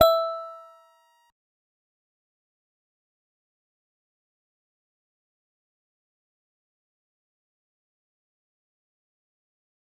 G_Musicbox-E6-mf.wav